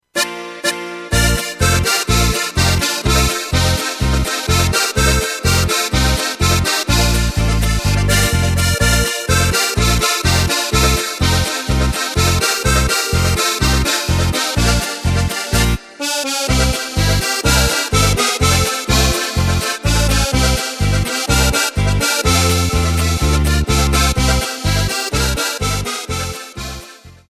Takt:          2/4
Tempo:         125.00
Tonart:            Bb
Wiener-Lied!
Playback mp3 mit Lyrics